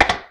Shield4.wav